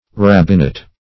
Rabbinite \Rab"bin*ite\ (r[a^]b"b[i^]n*[imac]t), n.
rabbinite.mp3